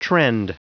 Prononciation du mot trend en anglais (fichier audio)
Prononciation du mot : trend